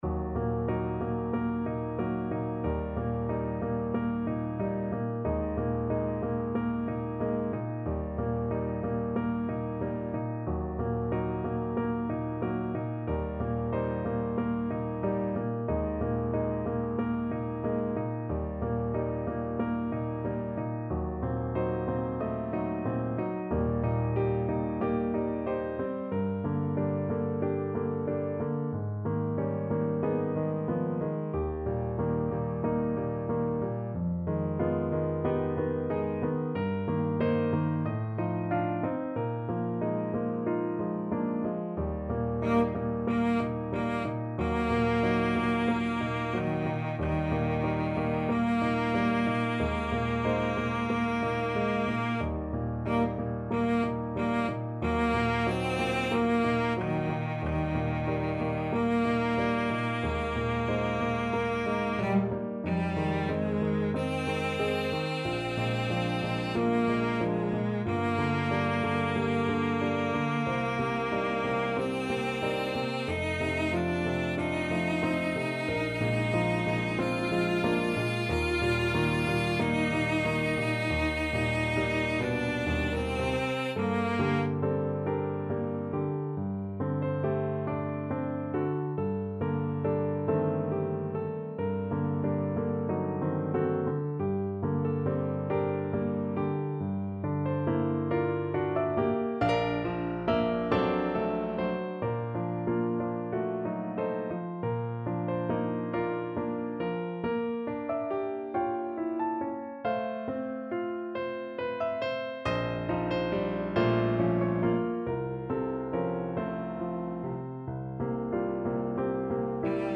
Cello version
Andante (=46)
4/4 (View more 4/4 Music)
Classical (View more Classical Cello Music)